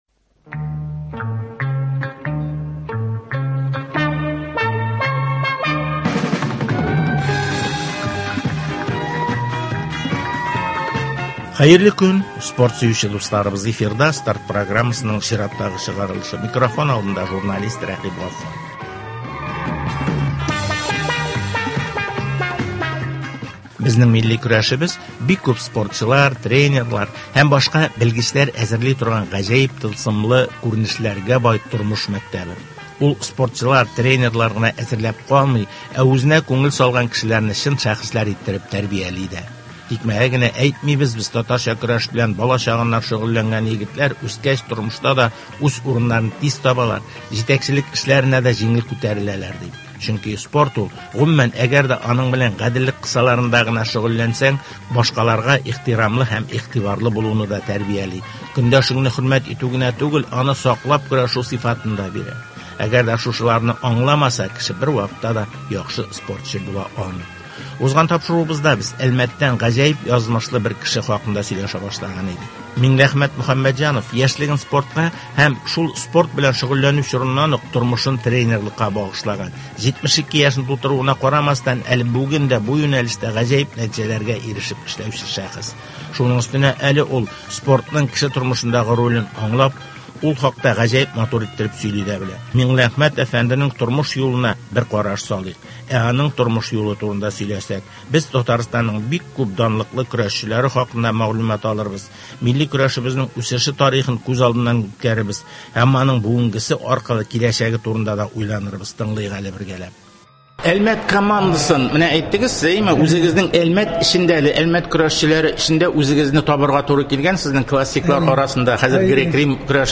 Казан – Россиянең спорт башкаласы, авылда спортның үсеше, дөнья күләмендәге чемпионатларга әзерләнү, районнар масштабындагы ярышларны үткәрү – әлеге һәм башка темалар хакында спортчылар, җәмәгать эшлеклеләре һәм спорт өлкәсендәге белгечләр белән әңгәмәләр.